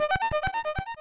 victory.wav